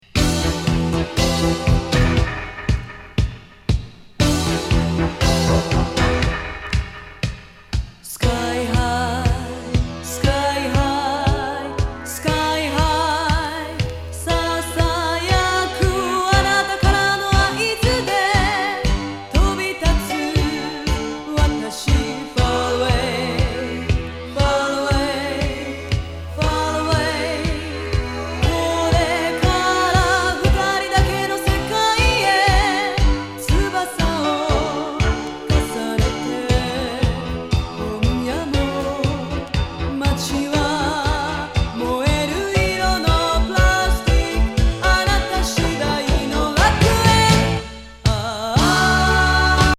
和レアリック・レフティ・ロッキン
和ファンキー・グルーブ